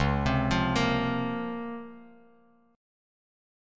DbM13 Chord
Listen to DbM13 strummed